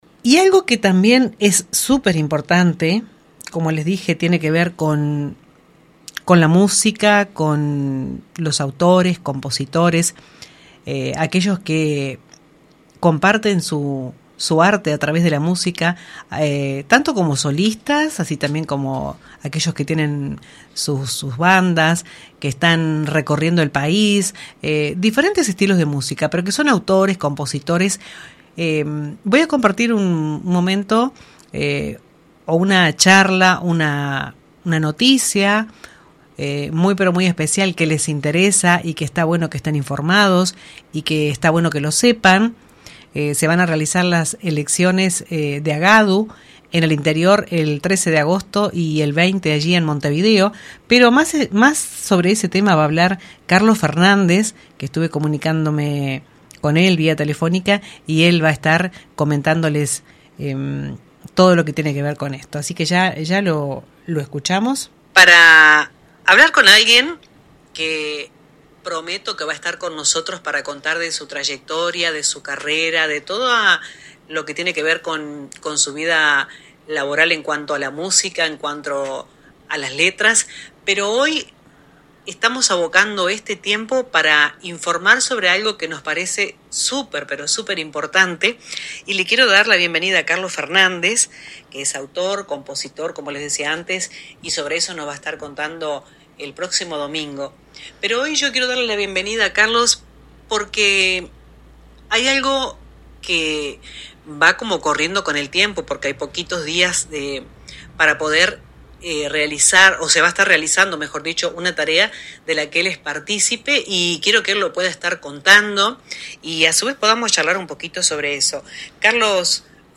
En su entrevista